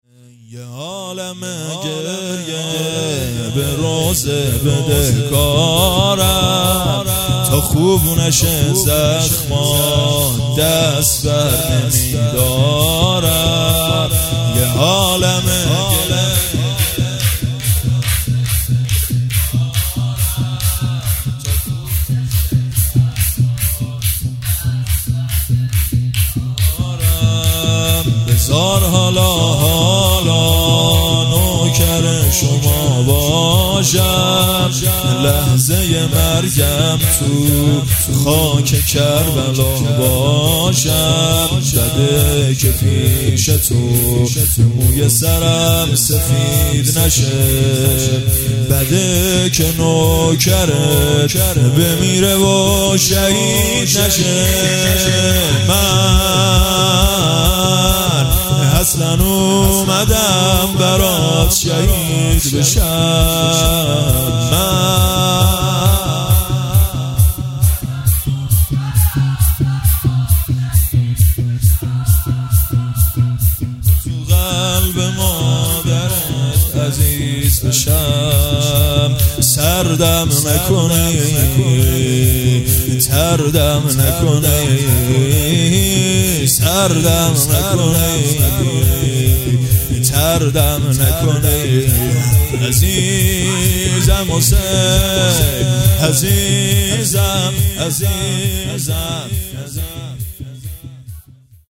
هیئت معزالمومنین(علیه‌السلام) قم
شور یه عالمه گریه